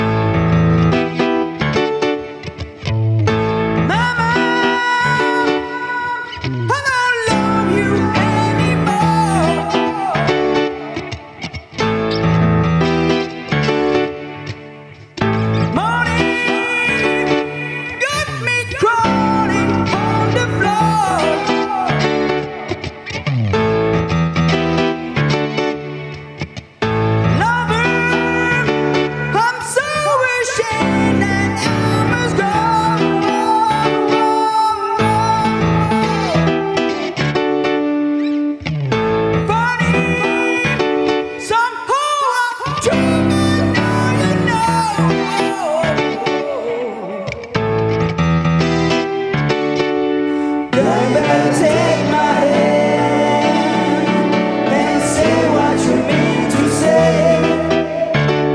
Acoustic Set